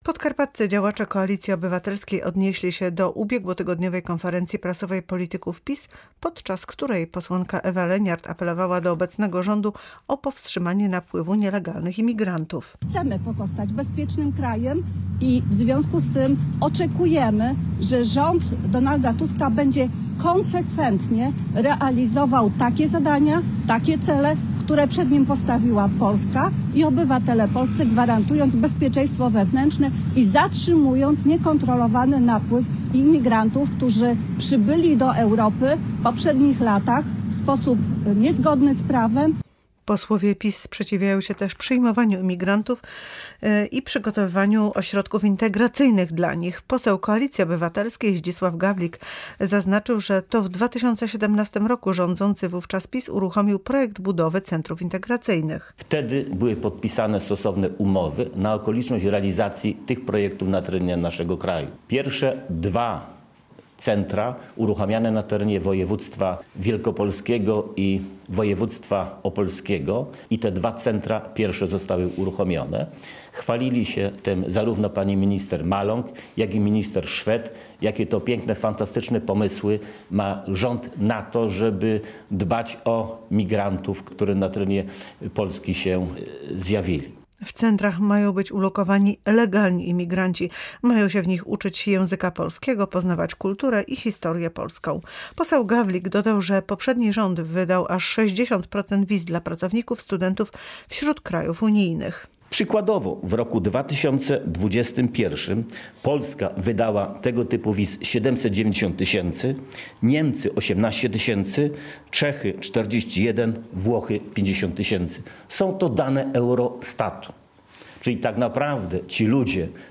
Imigranci pojawili się w Polsce jeszcze za czasów poprzedniego rządu – powiedział Zdzisław Gawlik, poseł Koalicji Obywatelskiej. 60% wiz pracowniczych i studenckich w całej UE wydano w Polsce przez Prawo i Sprawiedliwość.
Uruchomienie kontroli na zachodniej granicy uzależnione jest od wielu czynników stwierdził poseł Zdzisław Gawlik.
KO-konfa.mp3